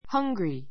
hʌ́ŋɡri